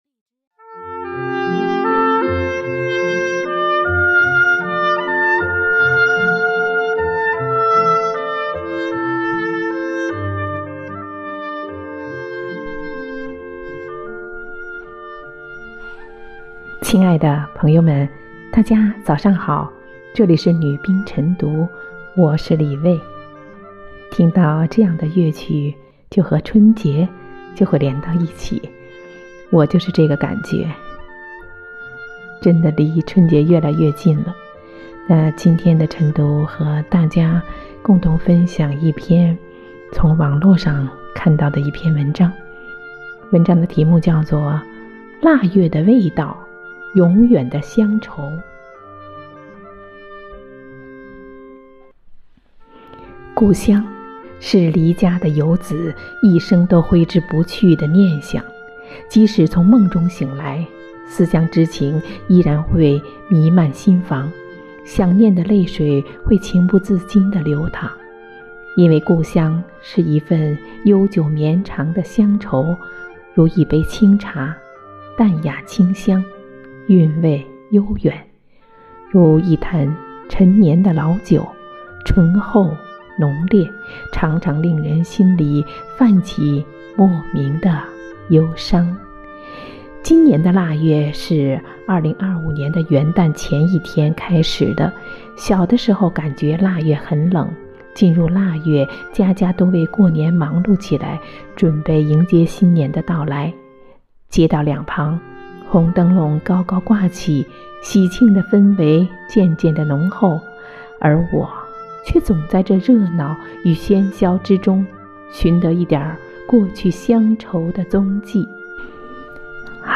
每日《女兵诵读》 腊月的味道，永远的乡愁